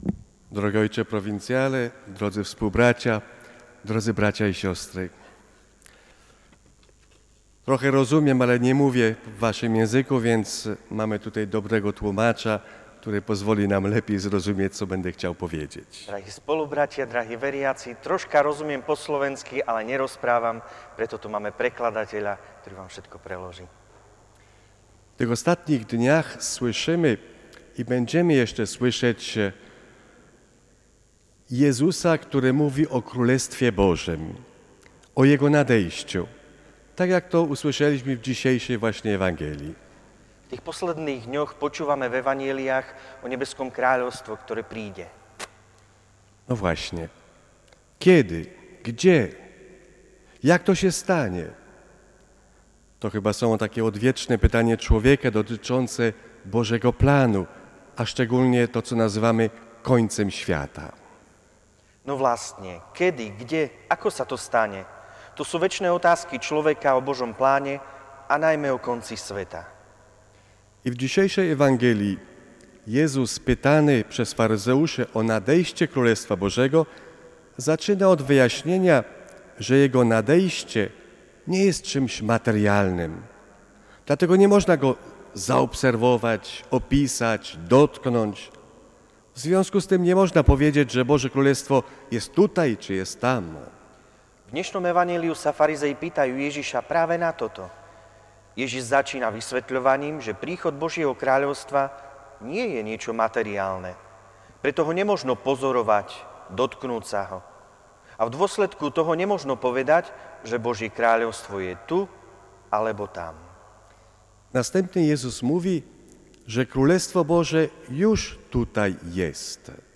V závere prítomní zaspievali spoločne predstavenému a otcom konzultorom mnoho rokov.